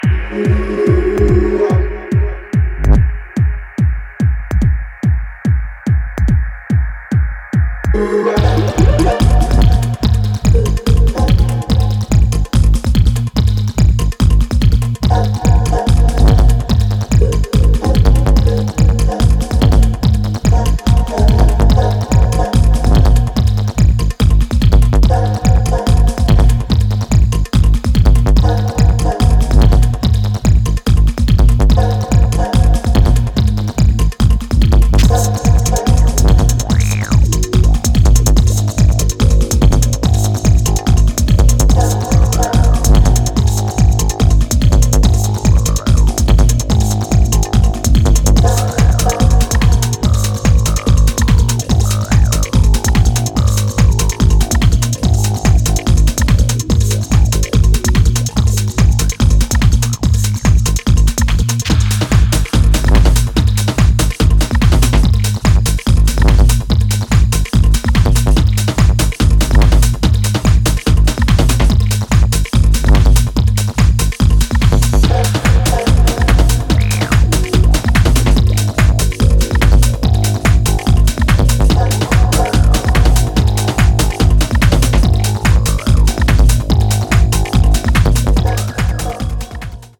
ブレイクビーツと4/4を並走させた傑作